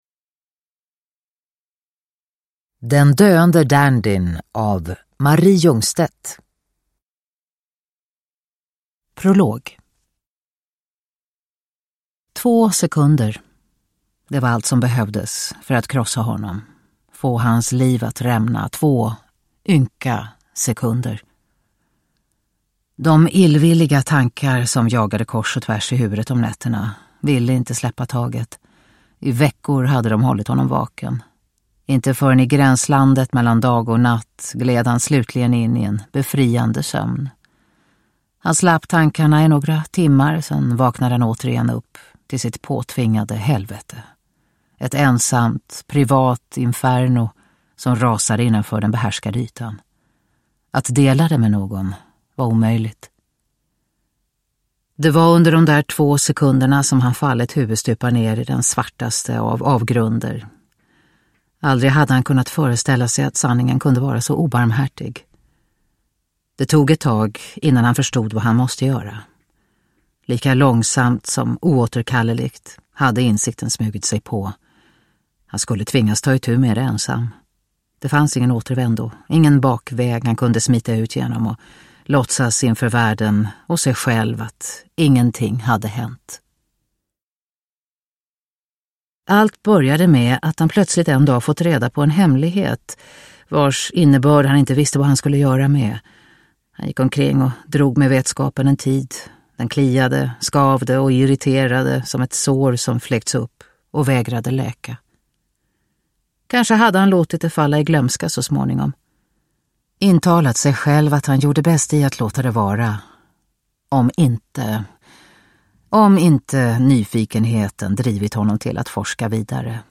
Den döende dandyn – Ljudbok – Laddas ner
Uppläsare: Katarina Ewerlöf